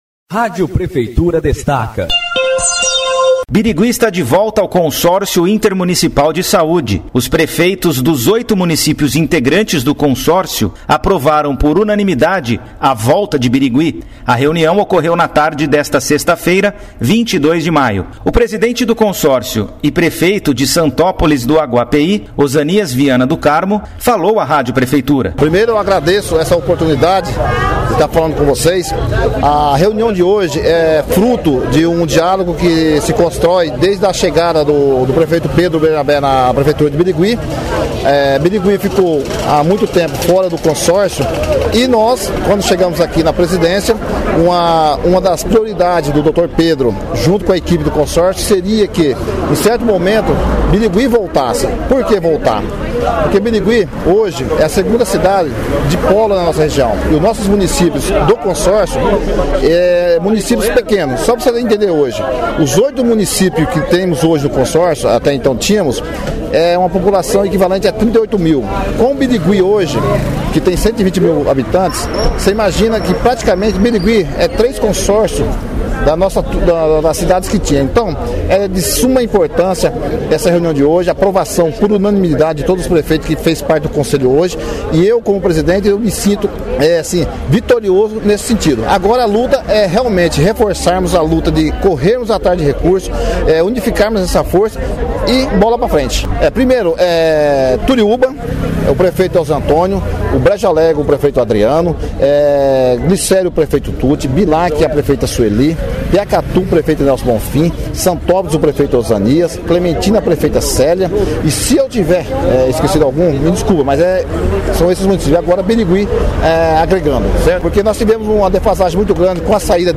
No link abaixo, a Rádio Prefeitura ouviu o prefeito Pedro Bernabé e a secretária de Saúde de Birigui, Andrea Benvenuta. O presidente do Consórcio também falou sobre o retorno de Birigui.